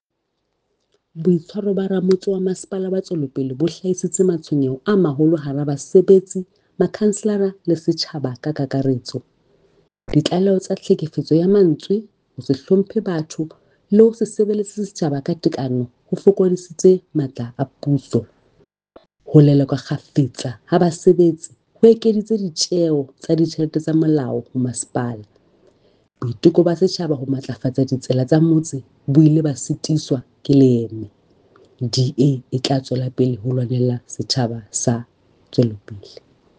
Sesotho soundbite by Cllr Mahalia Kose